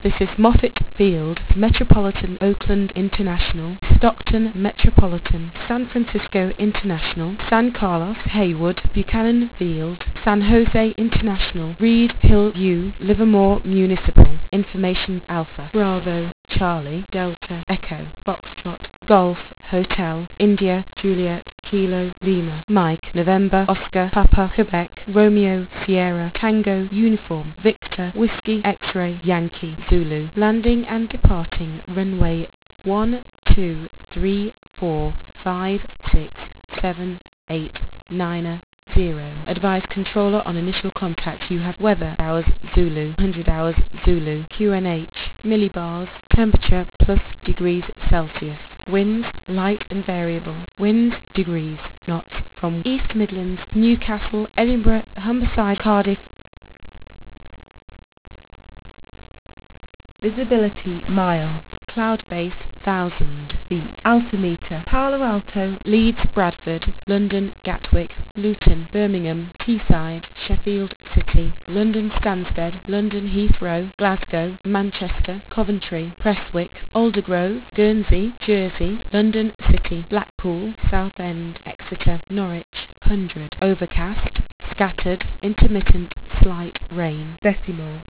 ATIS voice file was far too quiet - normalised it to 0dB